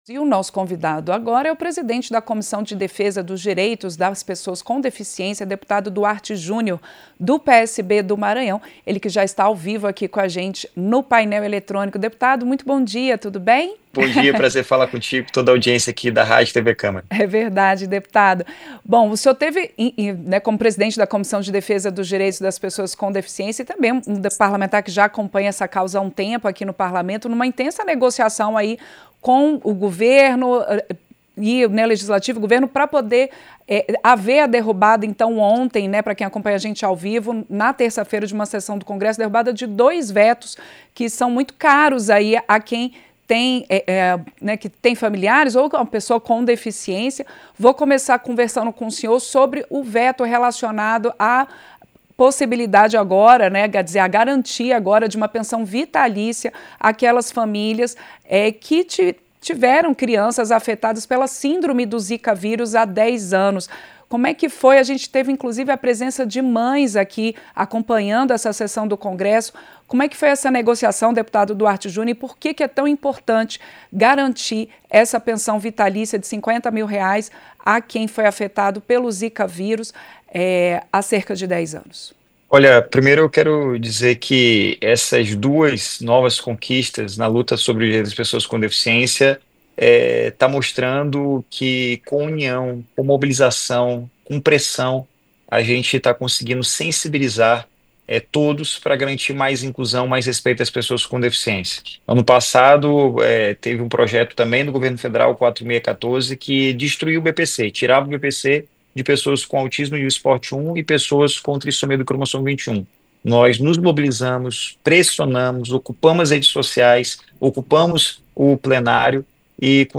Entrevista - Dep. Duarte Jr. (PSB-MA)